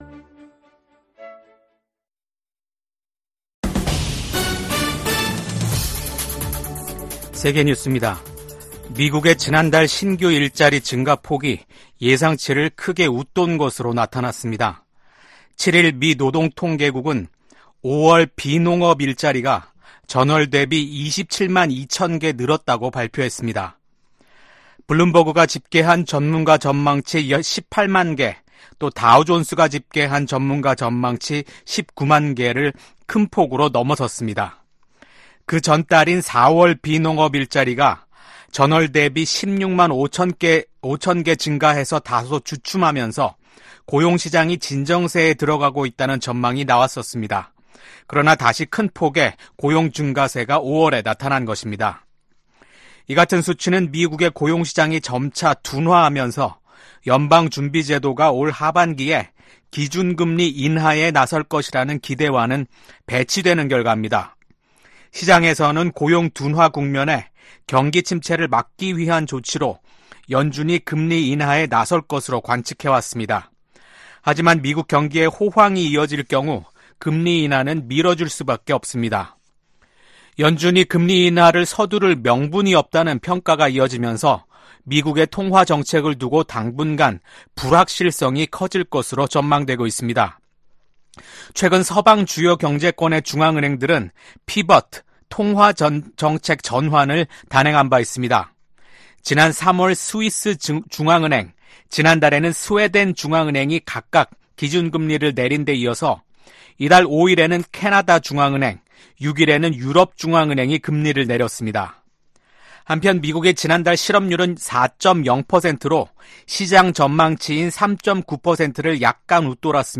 VOA 한국어 아침 뉴스 프로그램 '워싱턴 뉴스 광장' 2024년 6월 8일 방송입니다. 미국, 한국, 일본 3국의 협력은 인도태평양의 안보 구조와 정치 구조를 위한 ‘근본적인 체제’라고 미 국가안보부보좌관이 평가했습니다. 블라디미르 푸틴 러시아 대통령은 최근 세계 주요 뉴스통신사들과의 인터뷰에서 한국이 우크라이나에 무기를 공급하지 않고 있다고 이례적으로 감사 표시를 했습니다.